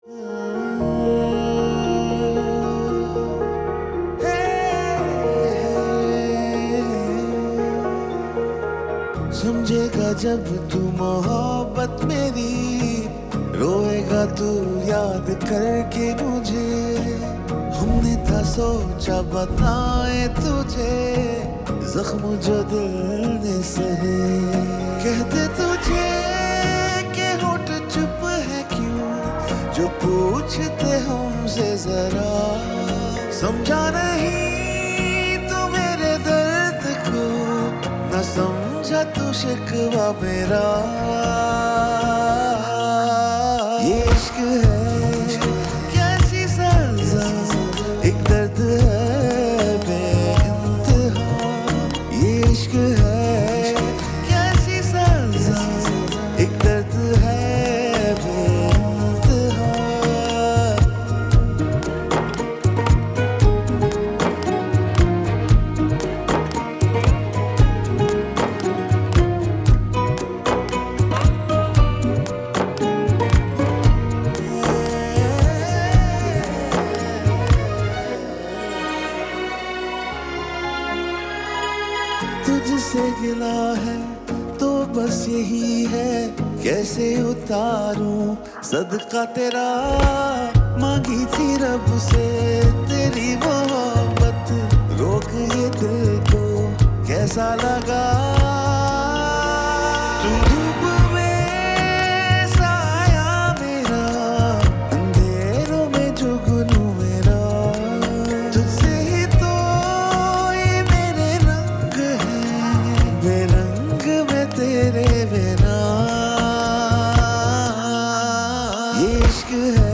Pakistani drama song